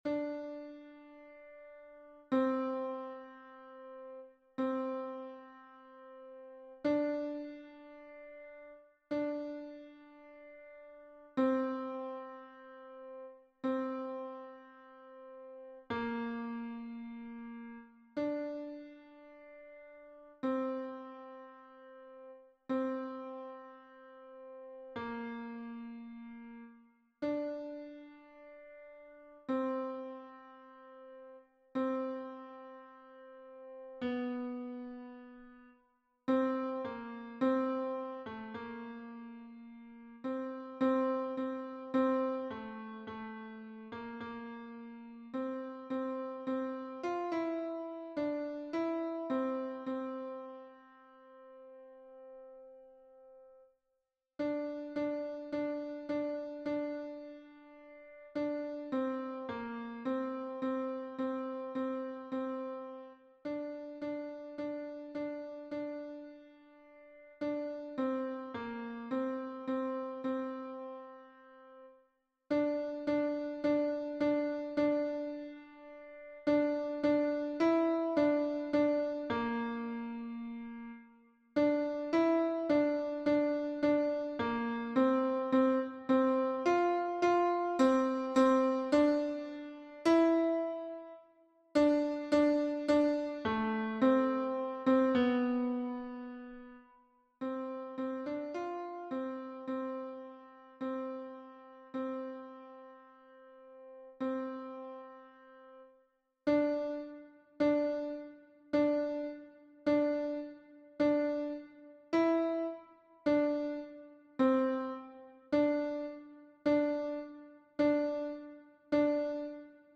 MP3 version piano
Alto 2 (en bas)